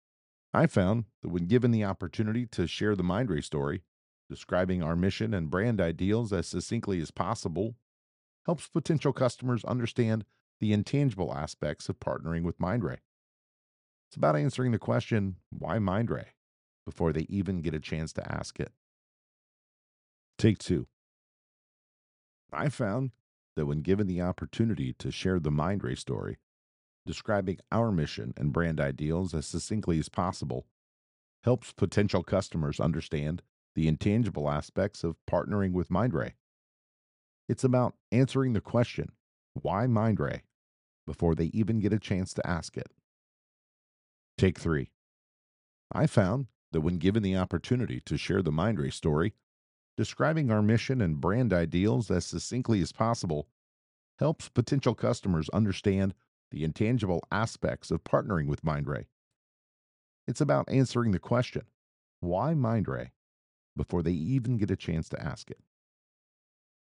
Promo - "Mindray" (:20)